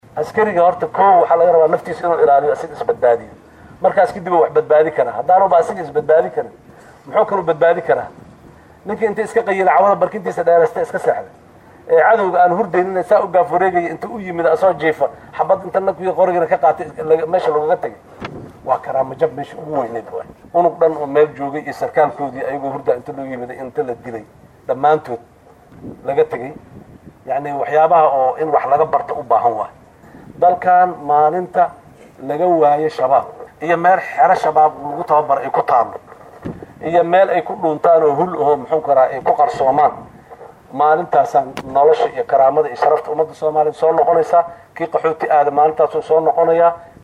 Muqdisho(INO)-Madaxweynaha dowlada Soomaaliya Xasan Sheikh Maxamuud ayaa ciidamo uu tababar usoo xereyay la wadaagaan dhacdo xanuun badan isagoo waano u jeediyay ciidamadii shalay tababar loogu soo xiray jazeera.